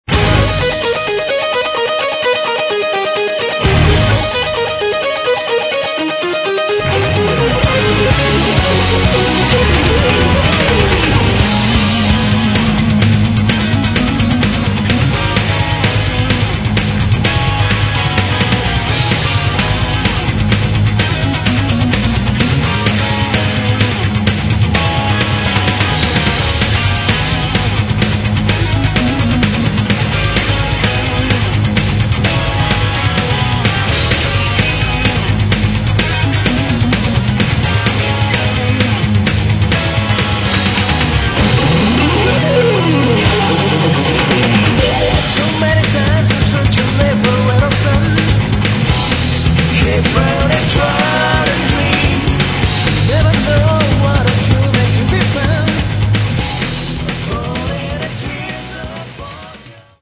Metal
гитара, все инструменты, вокал